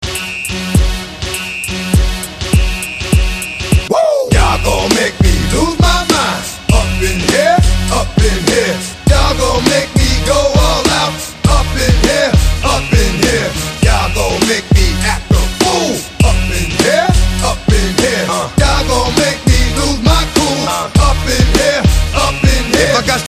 Категория: Rap, RnB, Hip-Hop